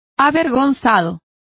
Complete with pronunciation of the translation of embarrassed.